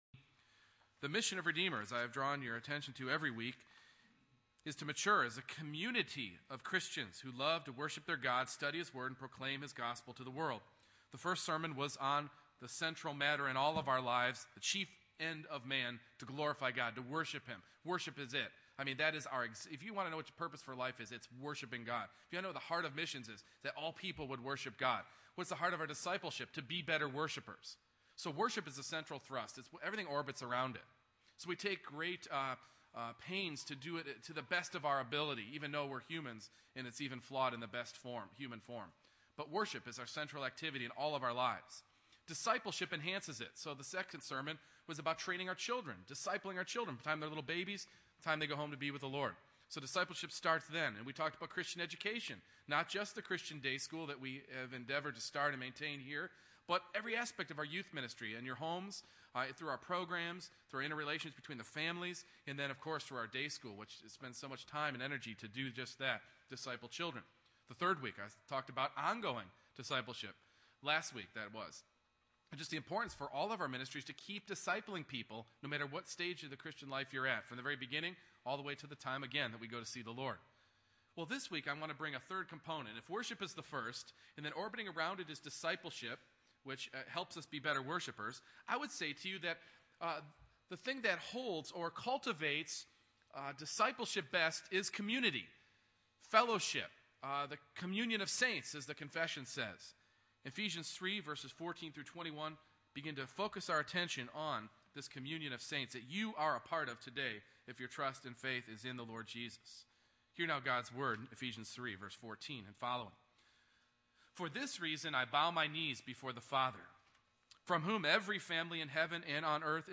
Ephesians 3:14-21 Service Type: Morning Worship Our central activity is to worship God.